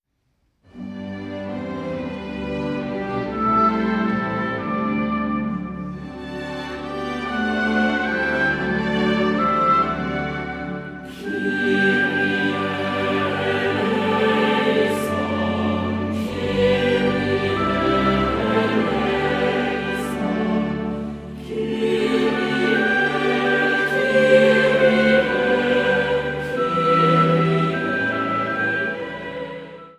Advents- und Weihnachtsmusik
Orgel
eingespielt in der Michaeliskirche Leipzig